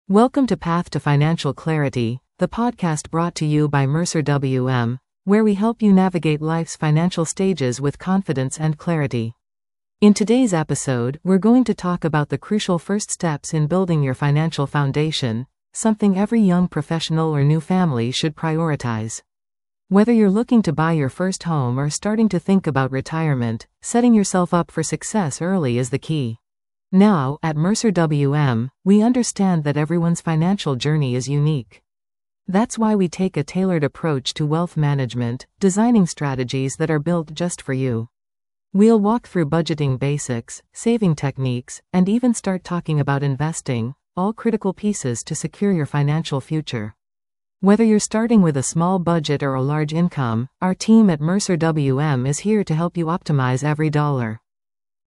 Hosted by Mercer WM expert advisors, each episode will provide listeners with practical advice, actionable strategies, and thought leadership on how to achieve financial security and peace of mind at every stage of life.
The podcast will feature interviews with Mercer WM’s experienced team, guest experts, and clients who have successfully navigated various financial milestones.